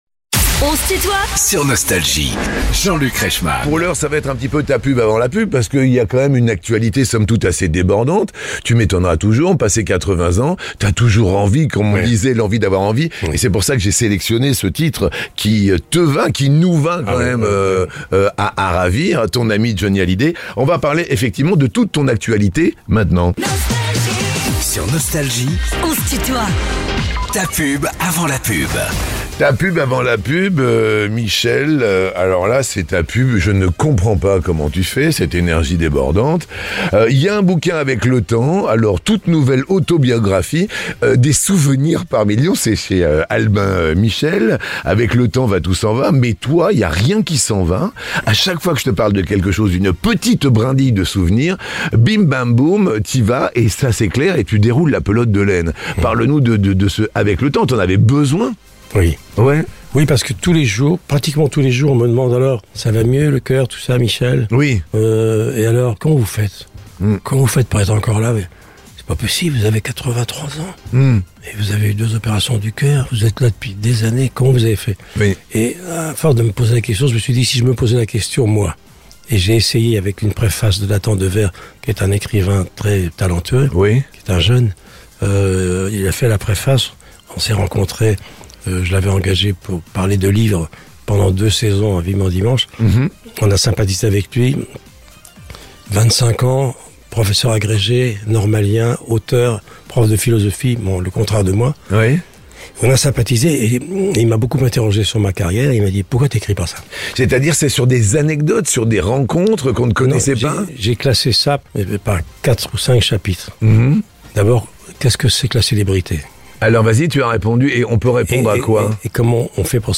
Michel Drucker est l'invité de "On se tutoie ?..." avec Jean-Luc Reichmann